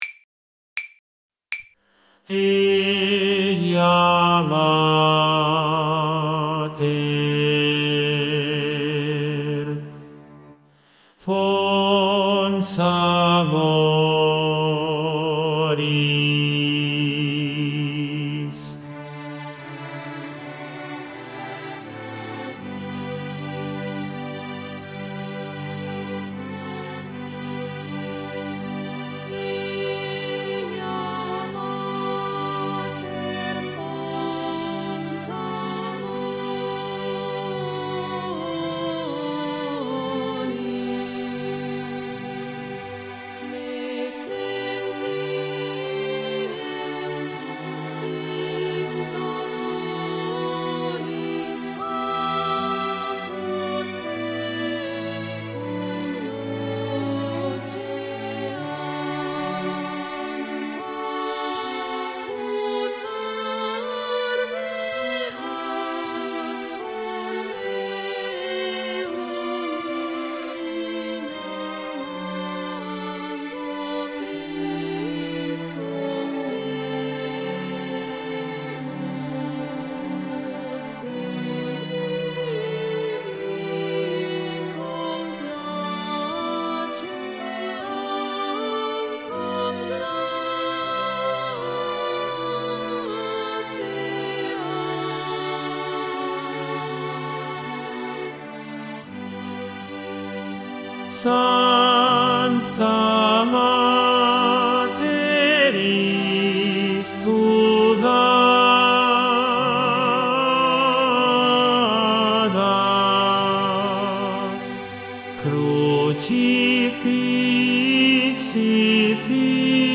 Tenor
👉 Descargar Mp3 Voz
Eja-Mater-TENOR-Profesor.mp3